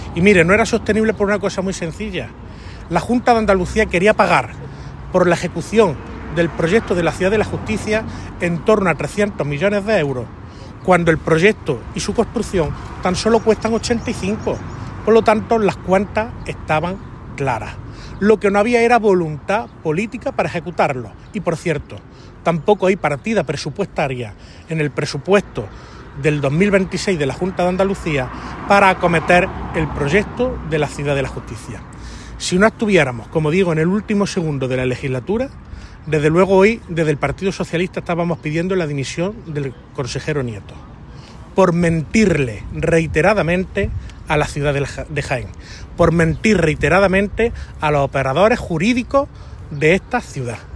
El parlamentario hizo estas declaraciones junto al solar de la Ciudad de la Justicia, donde manifestó que al consejero de la Junta “se le ha acabado el terreno de juego y las excusas” con este proyecto, que a día de hoy “sigue sin una perspectiva clara después de 8 años de Moreno Bonilla”.